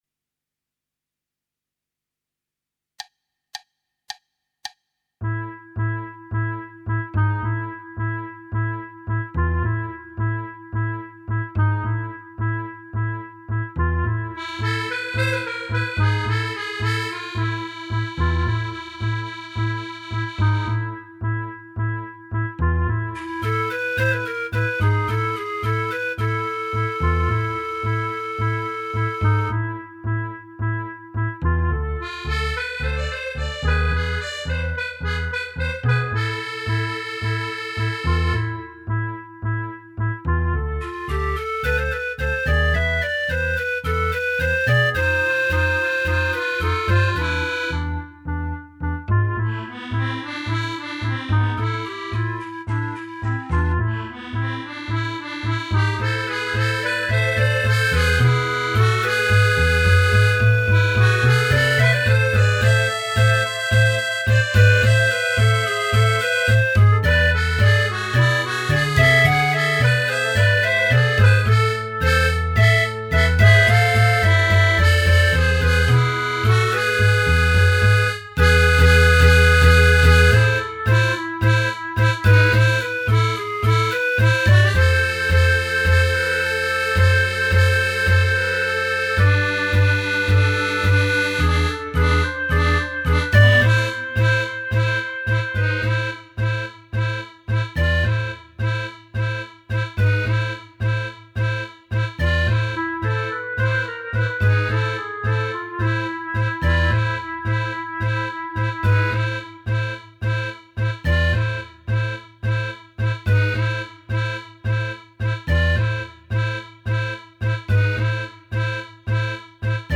Right click to download Tango minus Instrument 1